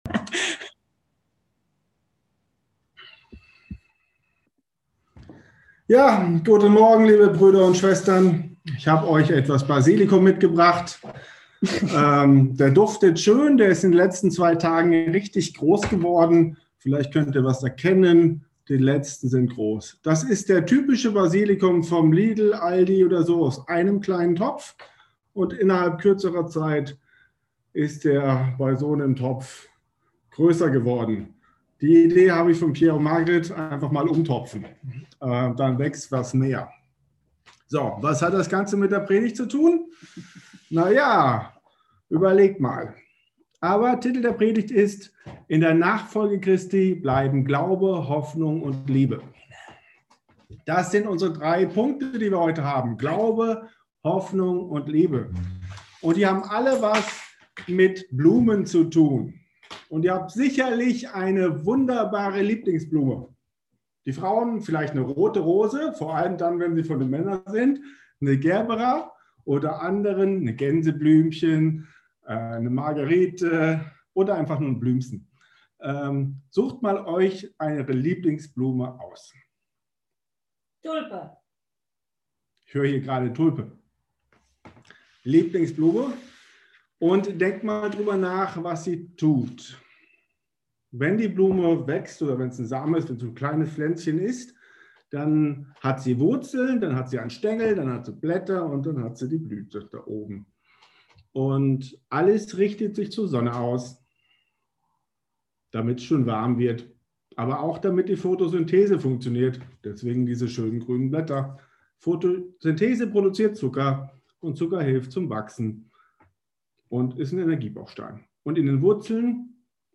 Predigt